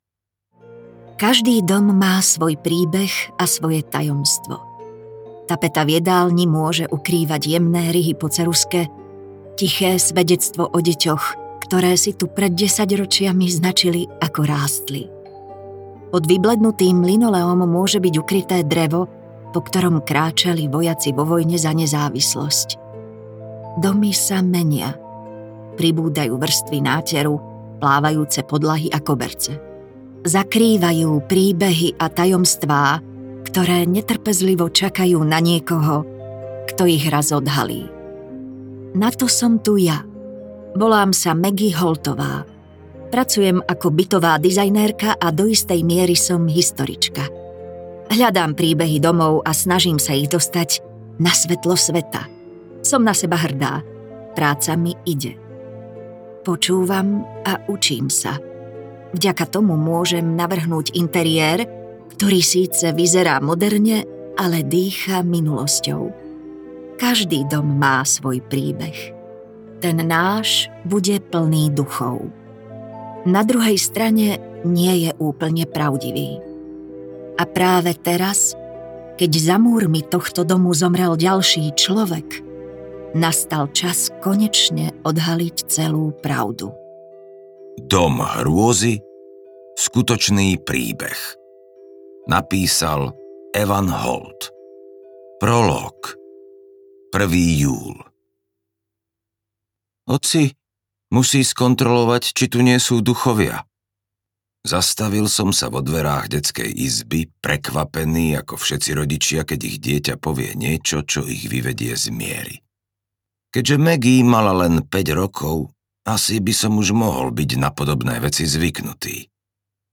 Keď prichádza tma audiokniha
Ukázka z knihy